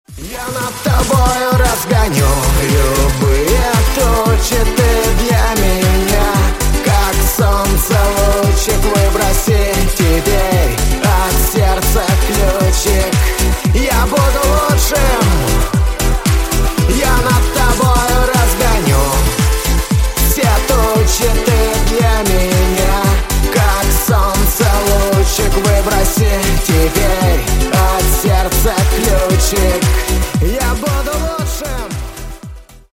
Шансон
Скачать припев песни: